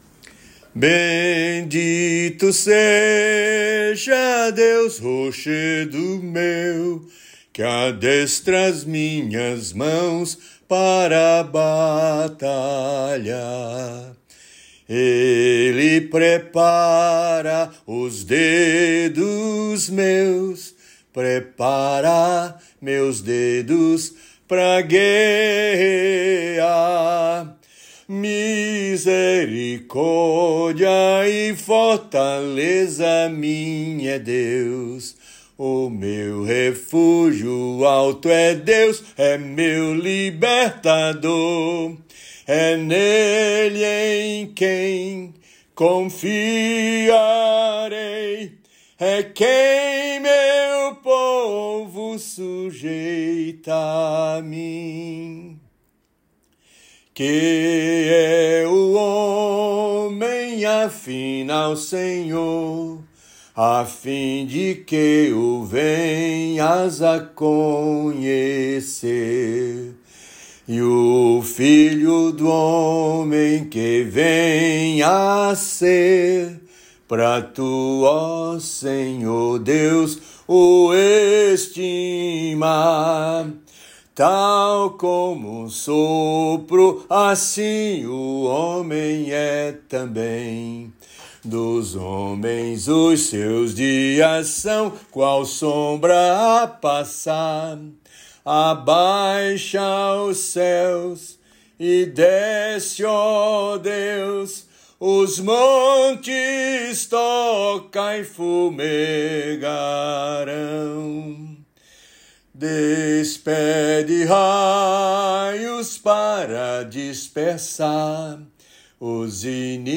salmo_144B_cantado.mp3